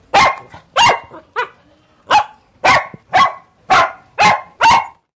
狗吠叫
描述：狗吠叫。 使用的设备是立体声Zoom H4n便携式录音机。
标签： 动物 叫声
声道立体声